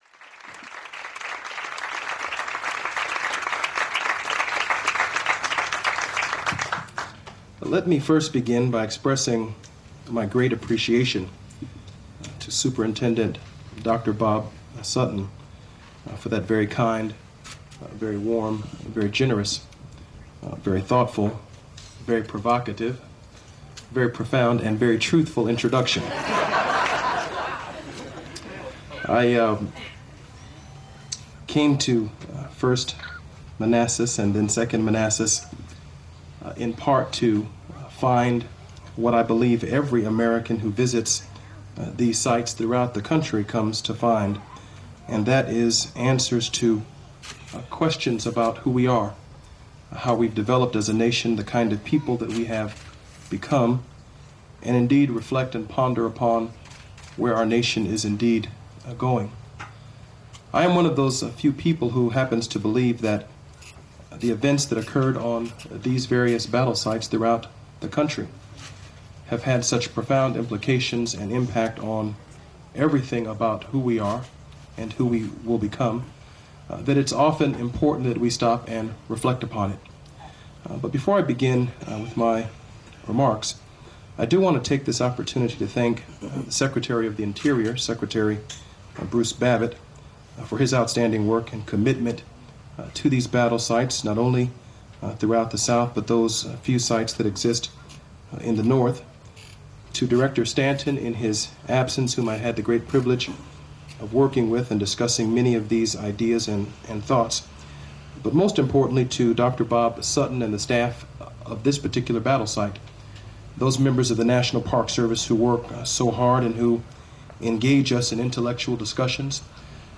In July we had the dedication of our new museum and lobby. Jesse Jackson Jr. was on hand to help with the ribbon cutting. to his speach. 13 minutes You need RealAudio player for this.